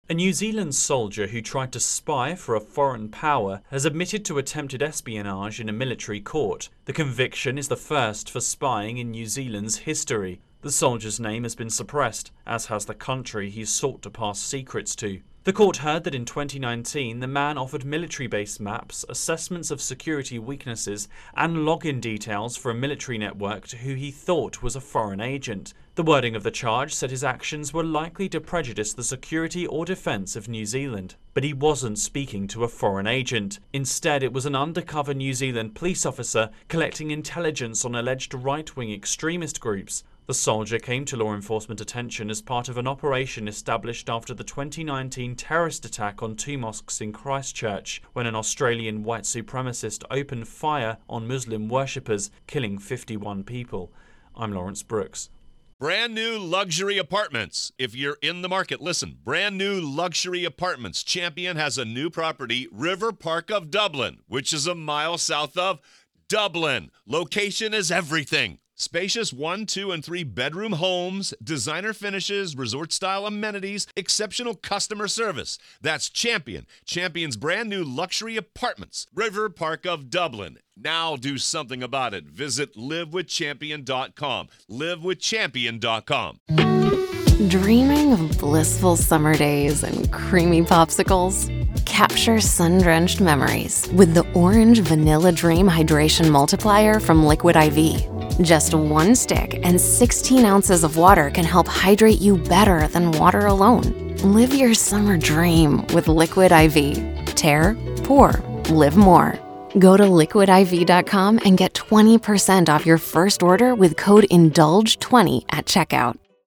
reports on New Zealand's first ever spying conviction.